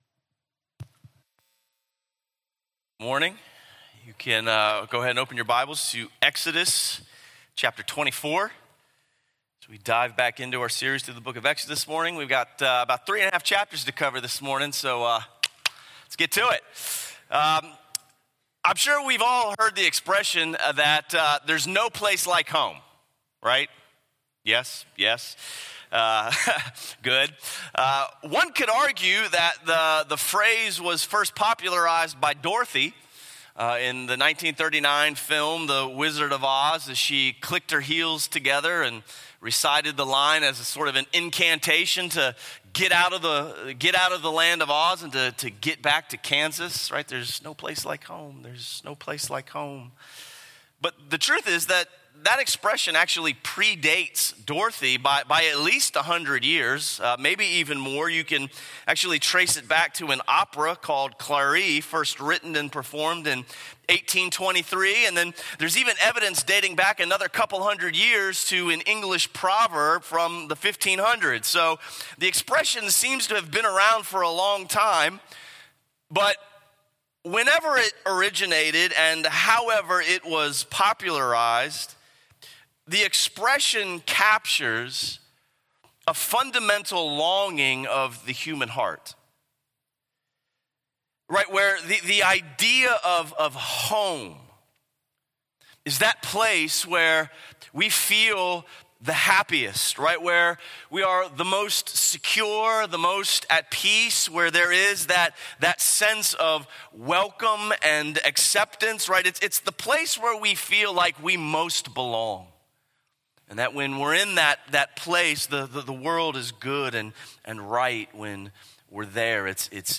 A message from the series "Life Under the Sun."